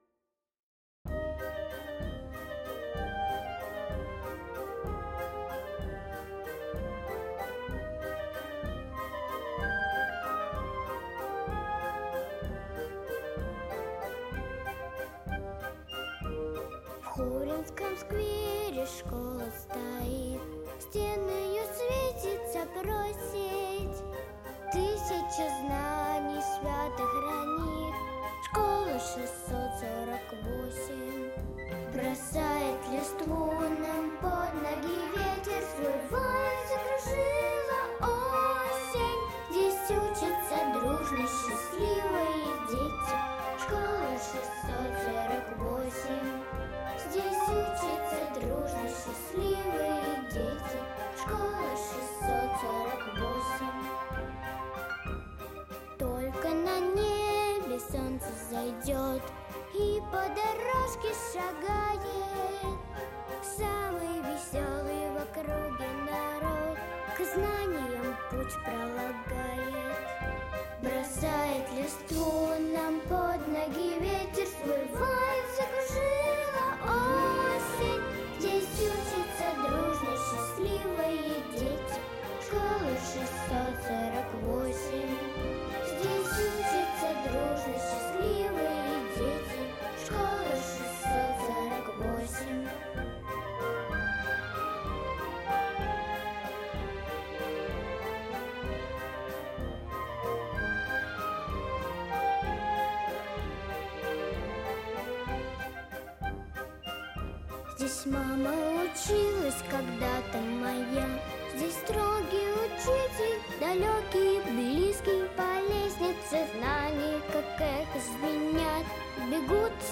Типа того, что потом балалайка с дормрой играют. А вот то, что балалайка с домрой играют - не вяжется с мелодией, надо подстраивать, чтоб было удобно петь.